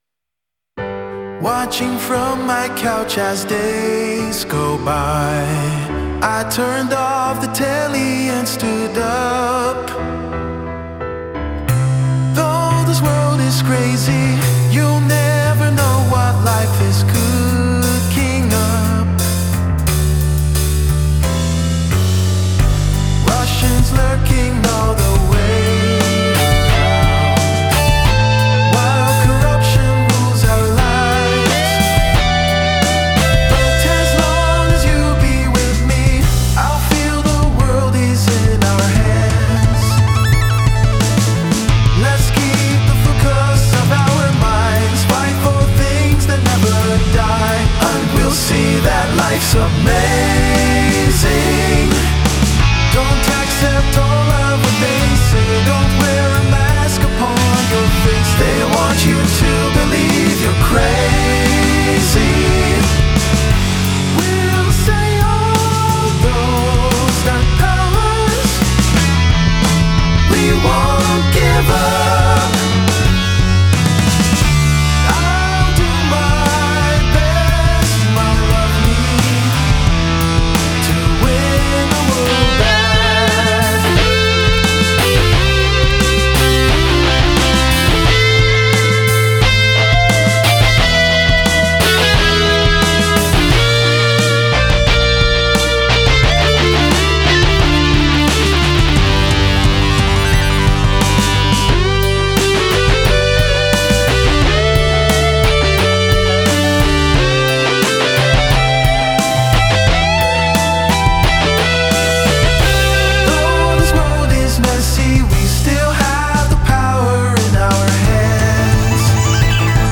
Me gustan las «cortinillas» entre frases musicales.
Rompes la baraja y haces algo raro en el séptimo compás para que no se note demasiado. El mic drop al final lo incluí porque me aburría y me planteé cerrar la cuenta en Spotify.